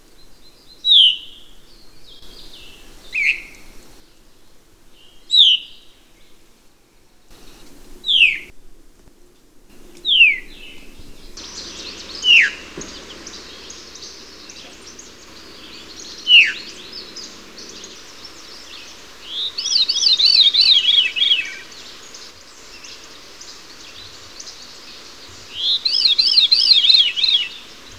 Veery
Catharus fuscescens
Zorzal Rojizo